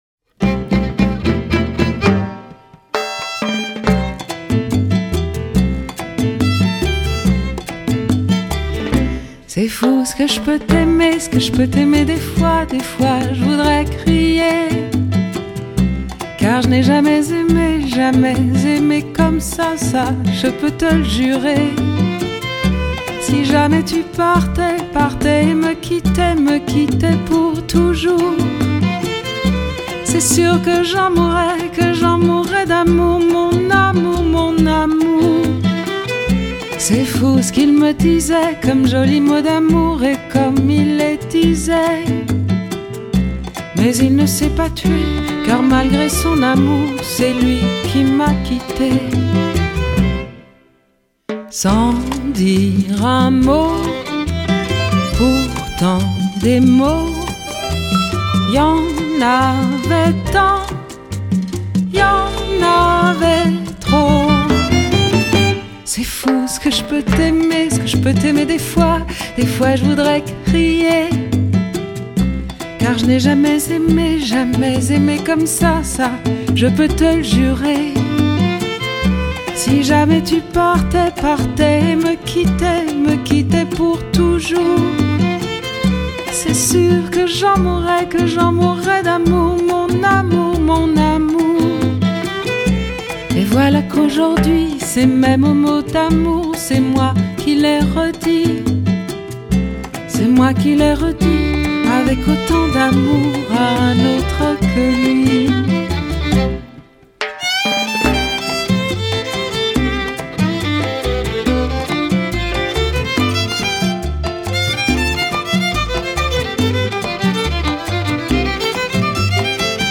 由她所演繹的歌曲常常是慵懶、舒緩中透露出些許悲傷。
很多作品也帶有極為深刻的爵士韻味。